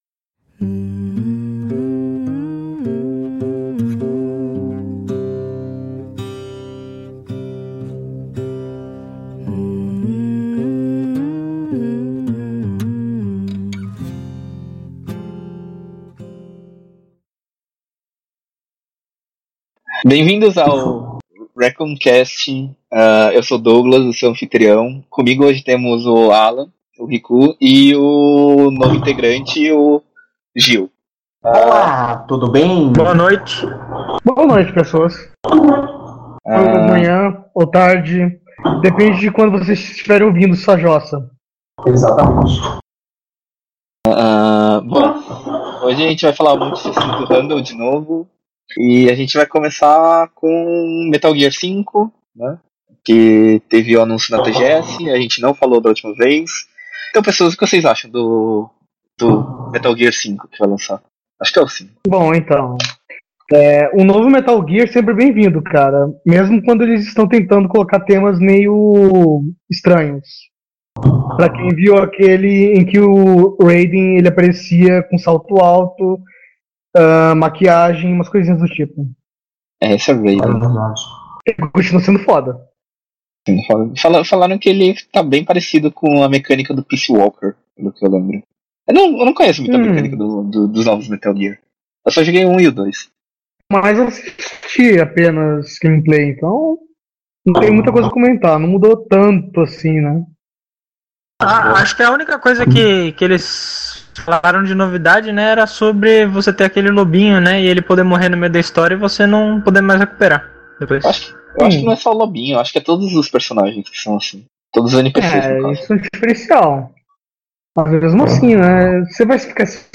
Participando desse caos em forma de podcast temos: